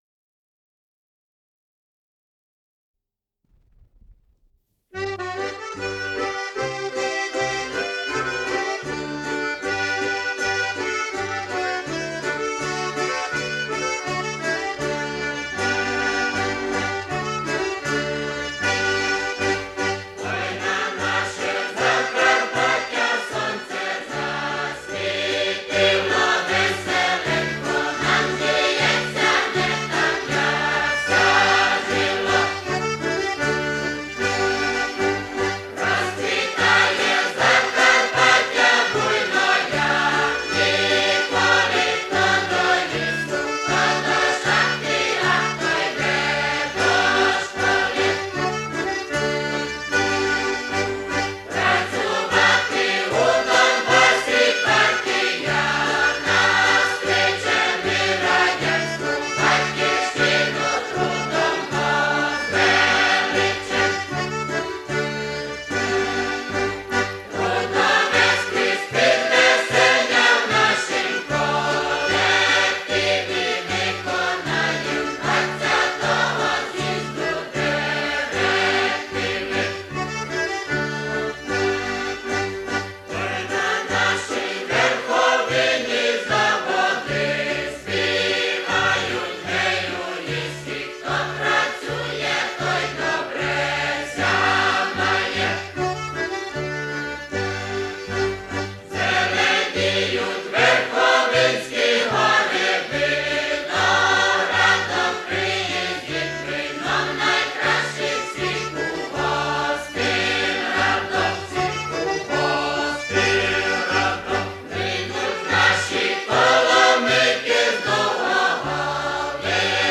ТОЛ-006 — Украинские народные песни — Ретро-архив Аудио